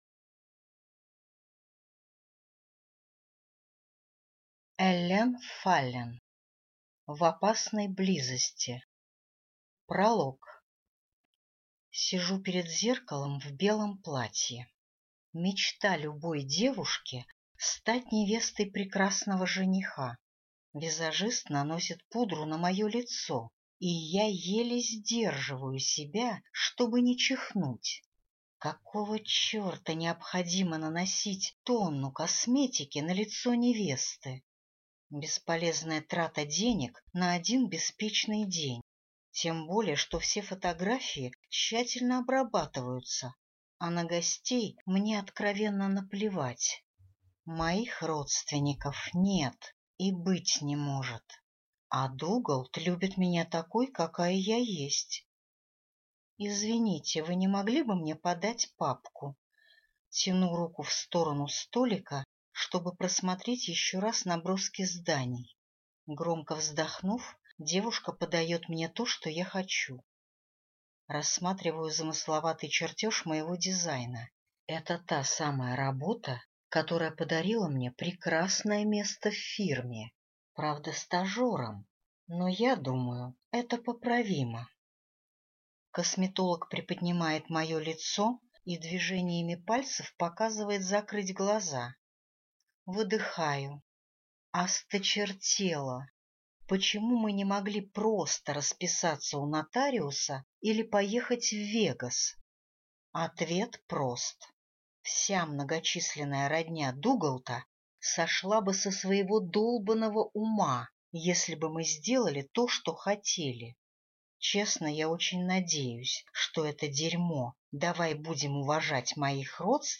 Аудиокнига В опасной близости - купить, скачать и слушать онлайн | КнигоПоиск